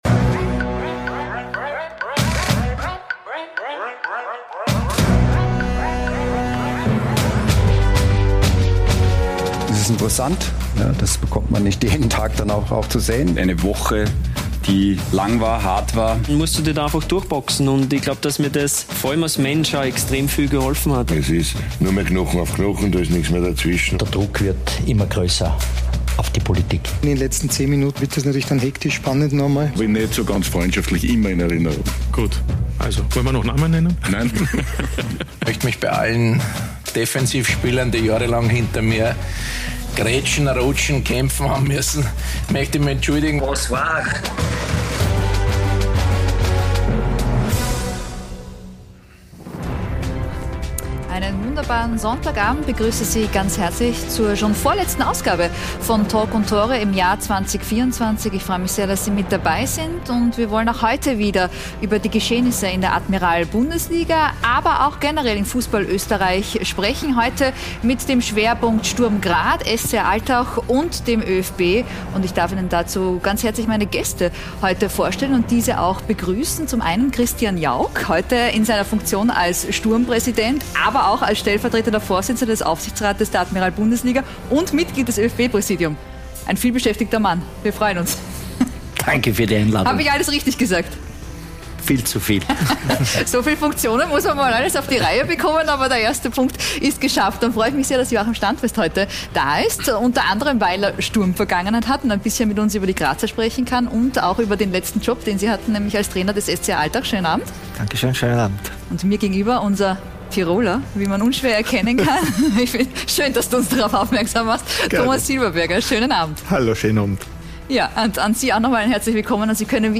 „Talk und Tore“ ist die erste und einzige Fußballtalksendung in Österreich. Wir liefern neue Blickwinkel, Meinungen und Hintergründe zu den aktuellen Themen im österreichischen Fußball und diskutieren mit kompetenten Gästen die aktuellen Entwicklungen.